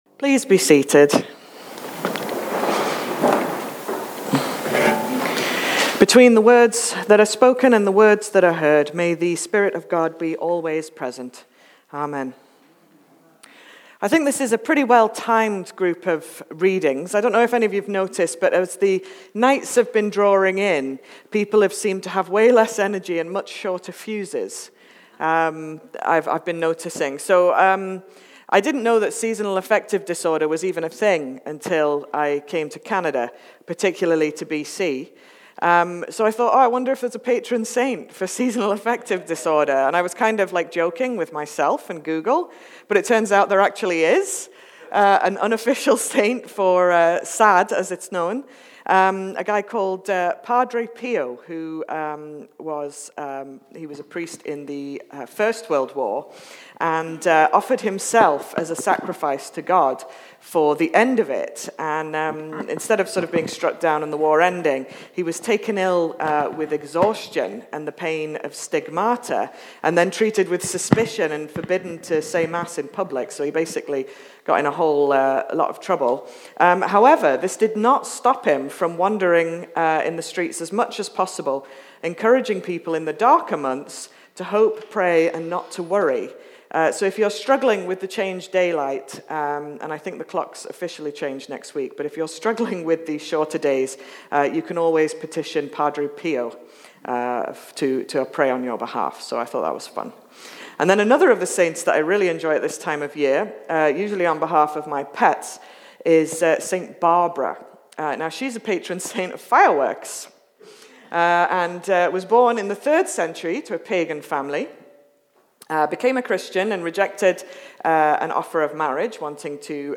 Sermon All Saints